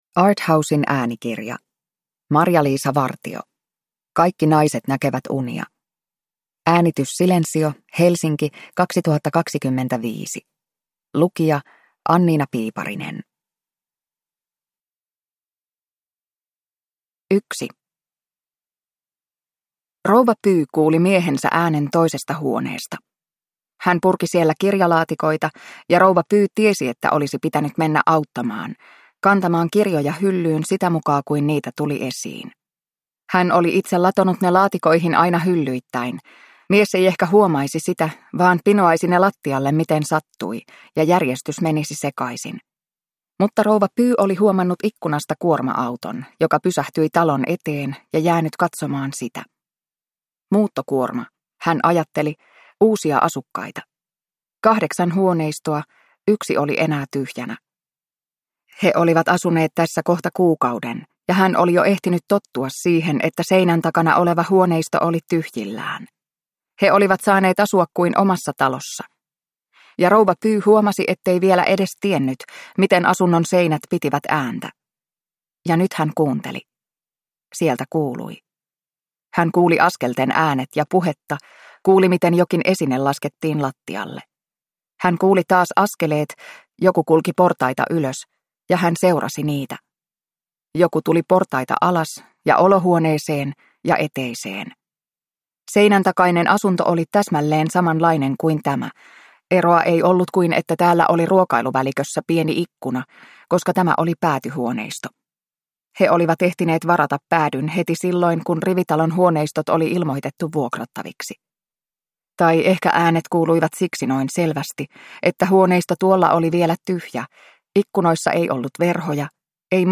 Kaikki naiset näkevät unia – Ljudbok